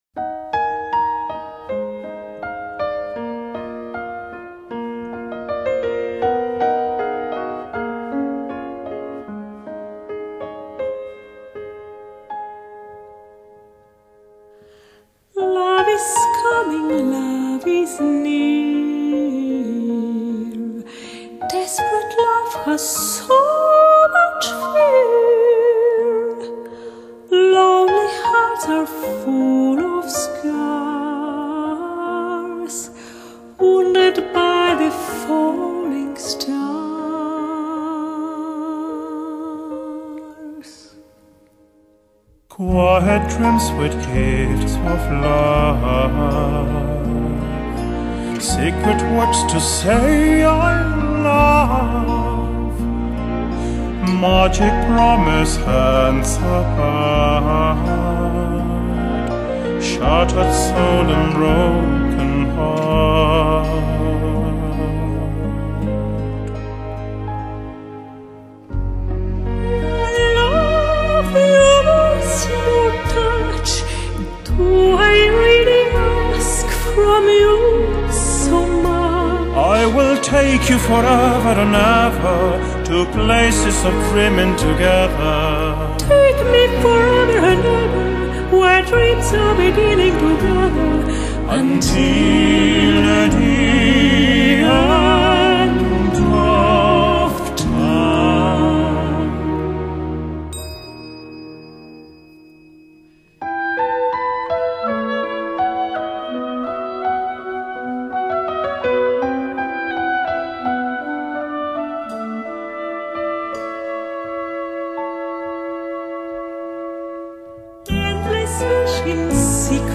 类型：Vocal
风格：Opera